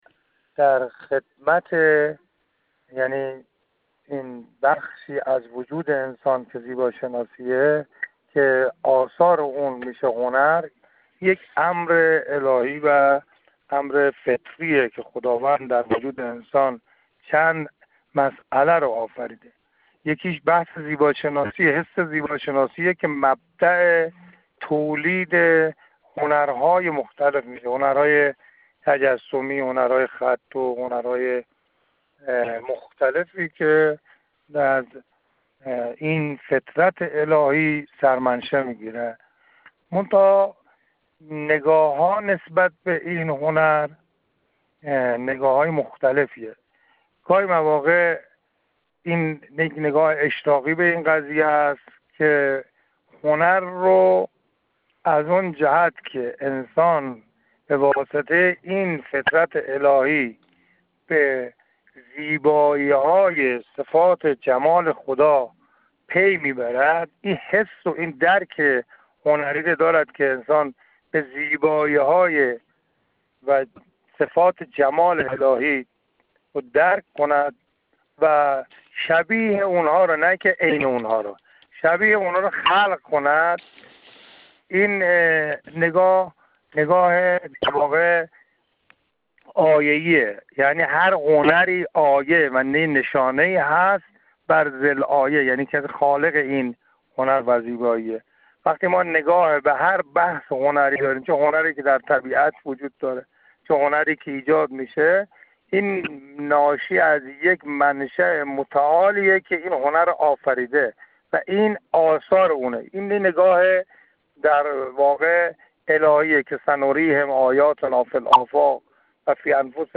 ایکنا با وی گفت‌و‌گویی پیرامون هنر اسلامی انجام داده که در ادامه با آن همراه می‌شویم.